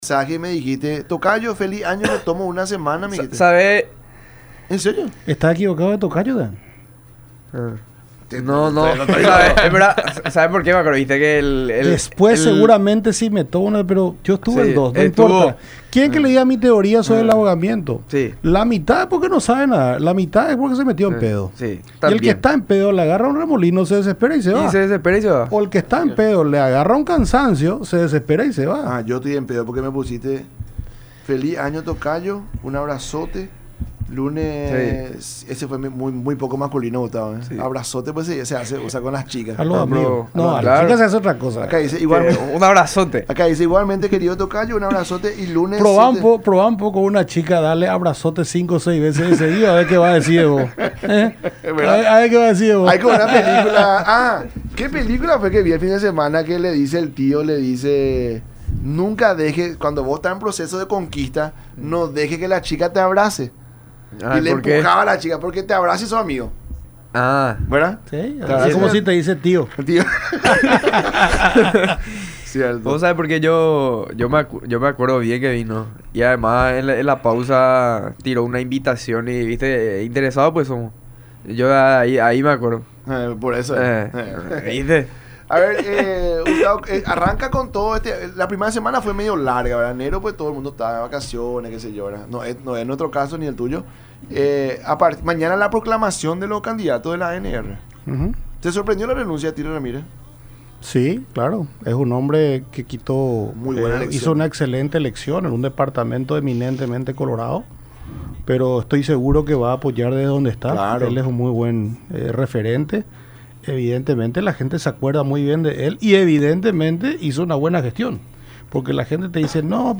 Si vos le convencés a la gente que la construcción del precio es así, cuánto cuesta el combustible, el flete, el impuesto selectivo al consumo y PETROPAR publica todo eso, la gente puede escrutar y ver que esa es la verdad y te va a creer”, dijo Leite en su visita a los estudios de Unión TV y radio La Unión durante el programa La Mañana De Unión.